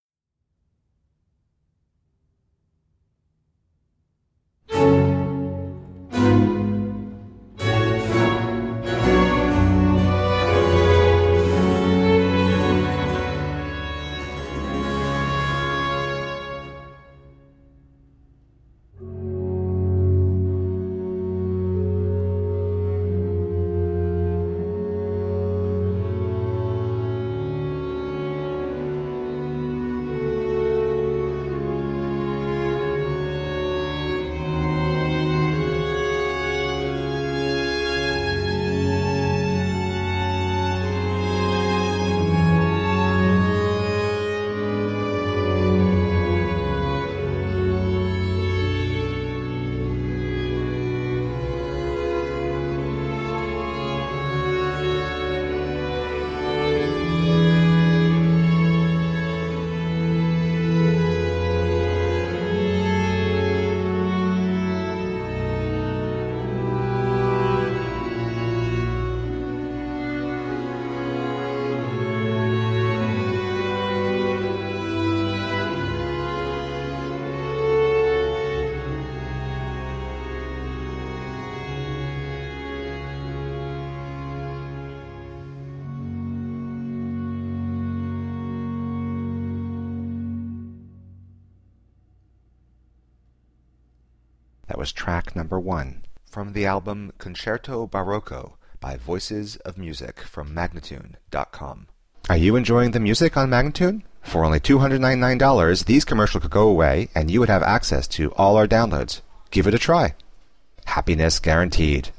Baroque Concertos